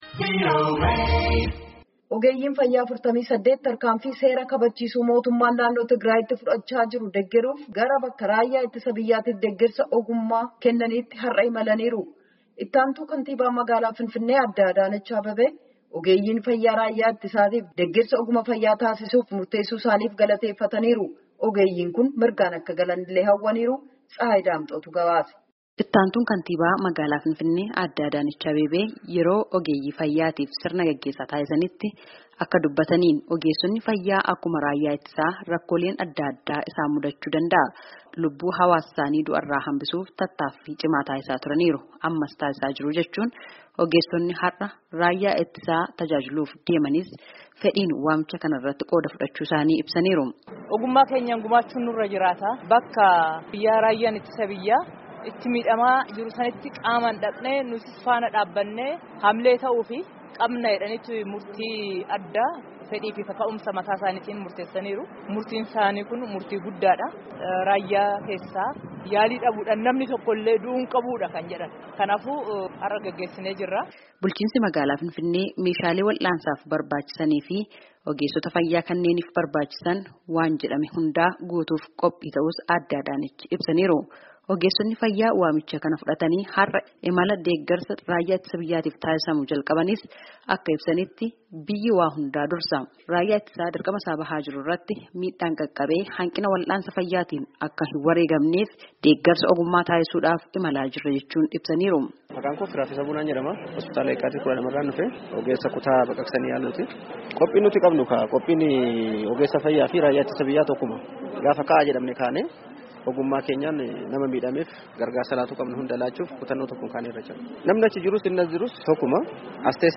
Gabaasaa guutuu caqasaa.